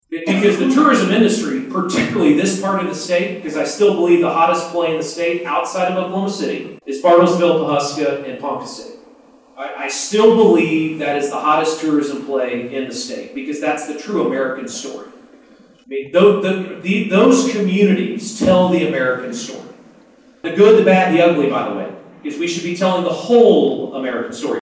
Oklahoma's Lt. Gov. Matt Pinnell was in Bartlesville Tuesday speaking at a Chamber Forum on multiple topics, including the state's third-largest industry, tourism.
Matt Pinnell on Bartlesville Region 10-15.mp3